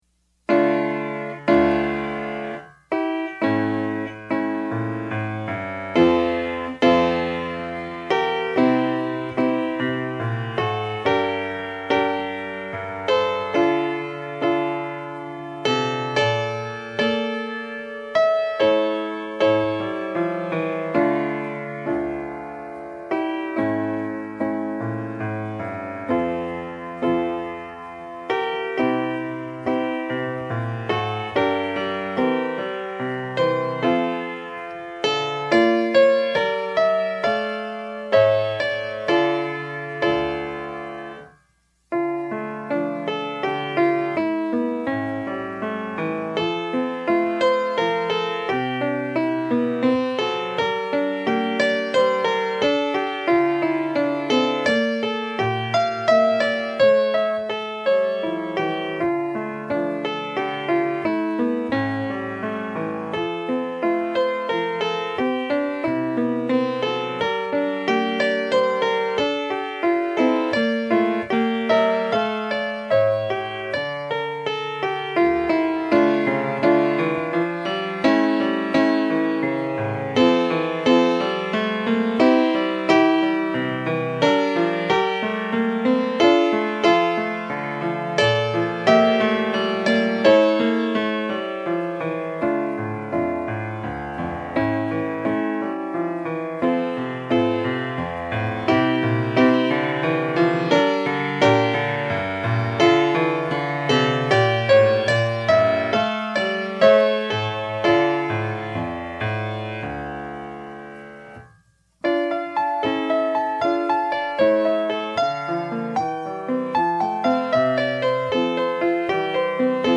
PIANO SOLO Sacred Music, Piano Solo, Prelude, & Offertory
DIGITAL SHEET MUSIC - PIANO SOLO